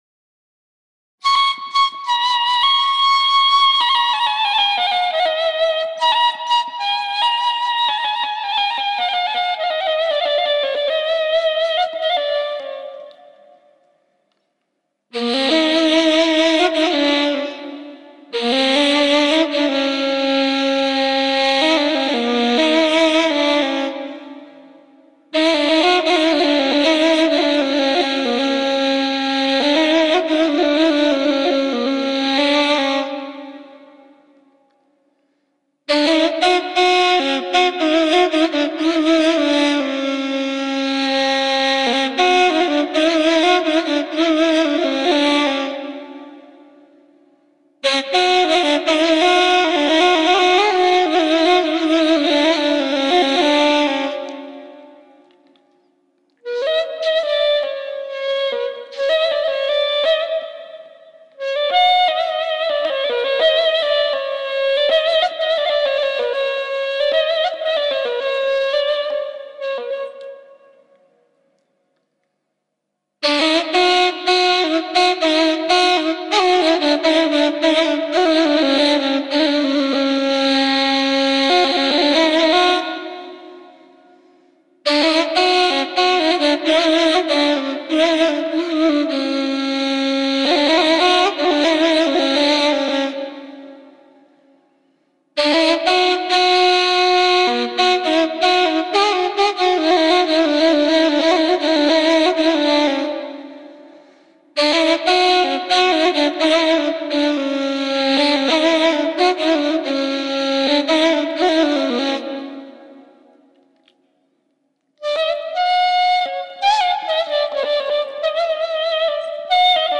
آهنگ دشتی بدون کلام سوزناک